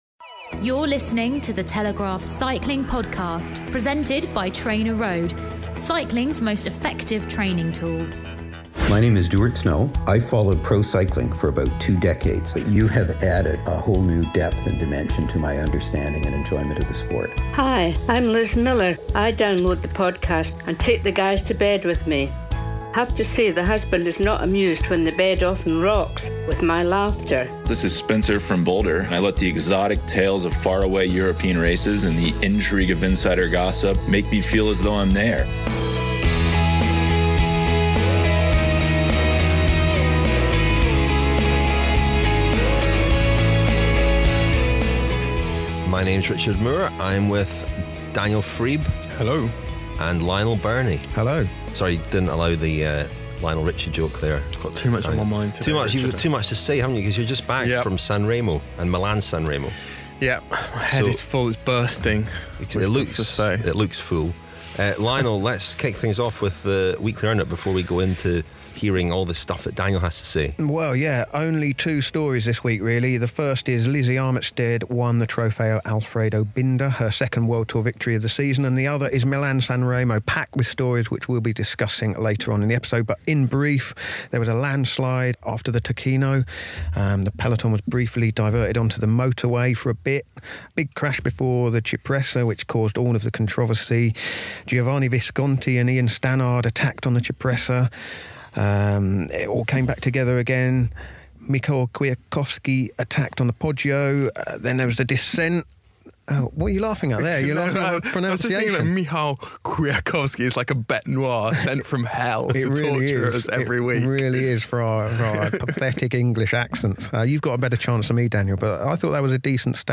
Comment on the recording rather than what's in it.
March 24 | This week’s Telegraph Cycling Podcast includes a special report from the first Monument of the season, Milan-San Remo. capturing the atmosphere in San Remo as the town prepared for the finish, speaking to the locals and then spending the day on Via Roma until the riders swept in